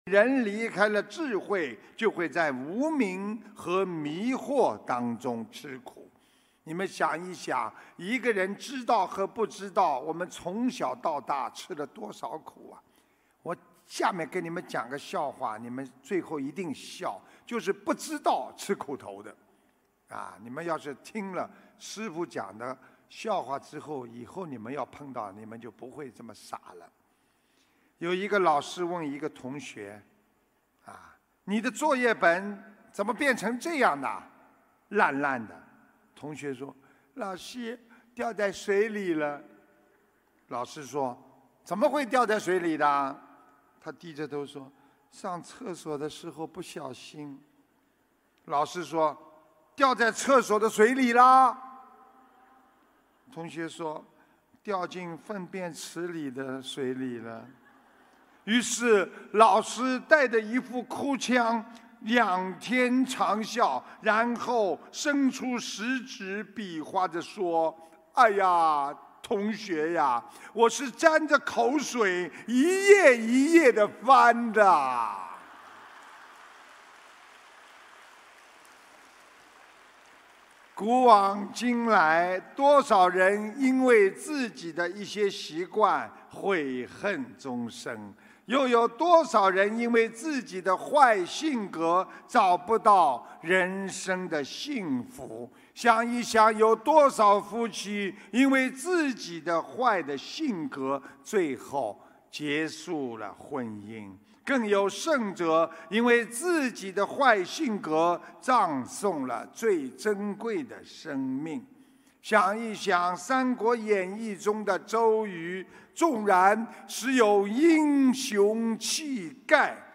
新加坡开示2019年5月10日